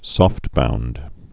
(sôftbound, sŏft-)